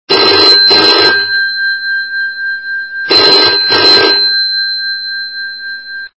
Old_phone.mp3.16.mp3